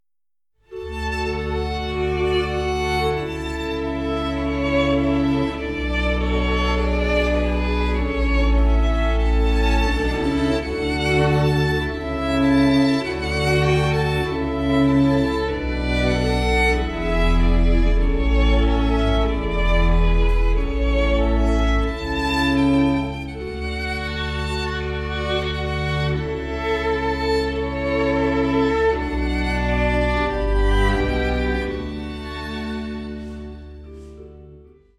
Streichensemble, Orgel